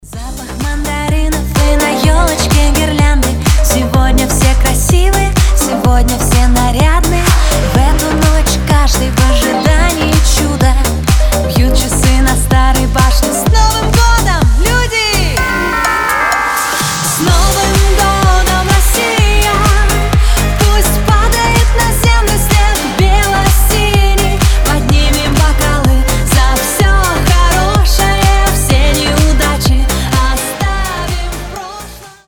• Качество: 320, Stereo
позитивные
добрые
праздничные
куранты